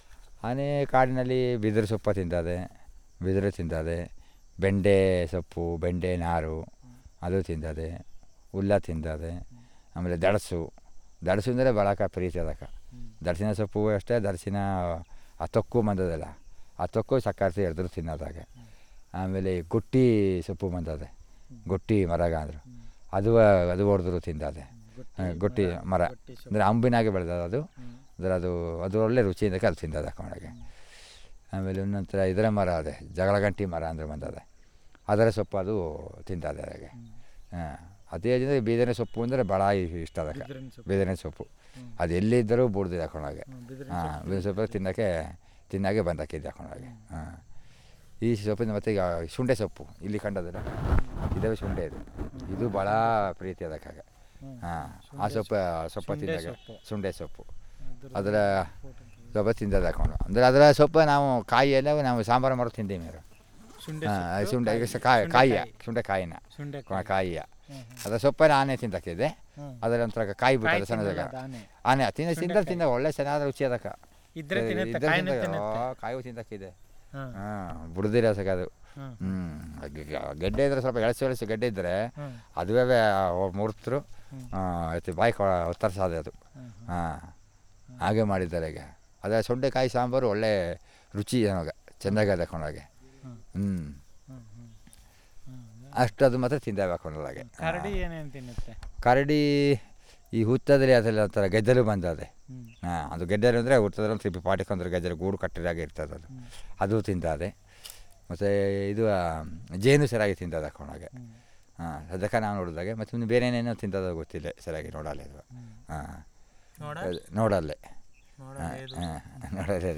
Elicitation of words about food eaten by wild animals